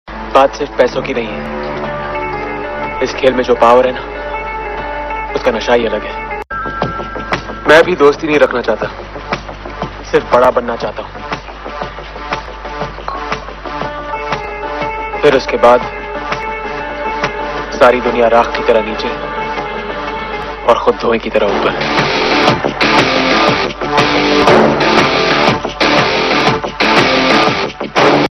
Dialogue Mp3 Tone